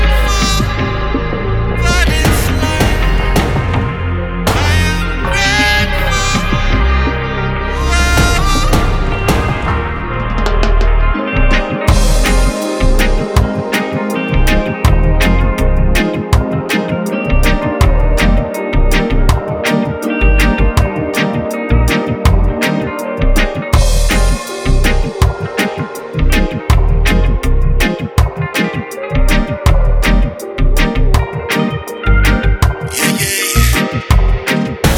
Жанр: Регги
Modern Dancehall, Reggae